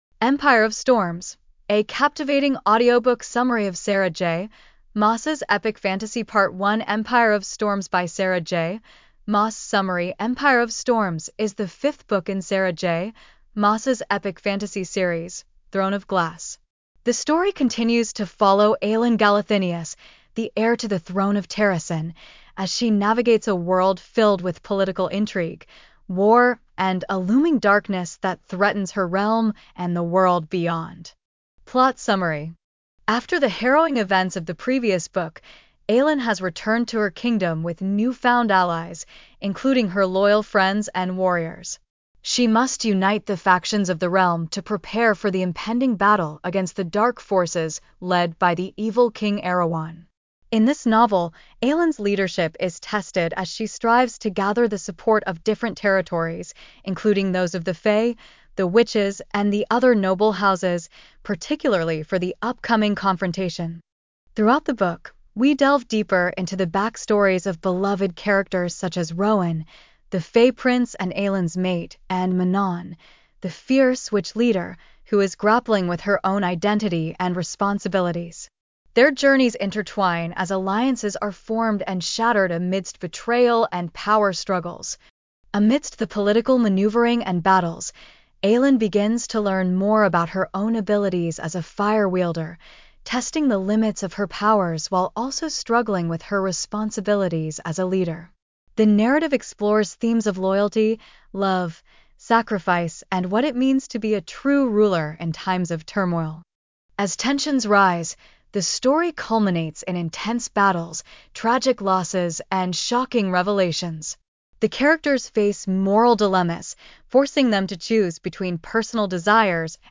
Empire of Storms: A Captivating Audiobook Summary of Sarah J. Maas's Epic Fantasy